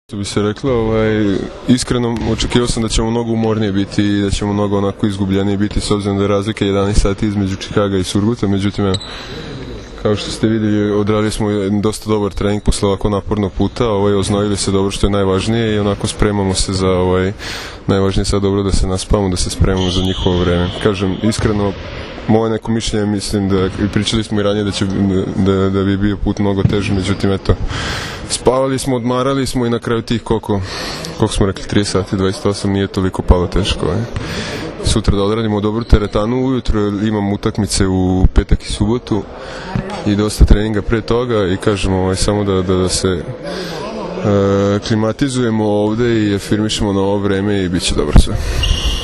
Pogača i so u rukama devojaka obučenih u rusku narodnu nošnju dočekali su, posle dugog puta iz Čikaga, seniore Srbije, uz TV ekipe koje su čekale da intervjuišu reprezentativce Srbije i članove stručnog štaba.
IZJAVA MARKA PODRAŠČANINA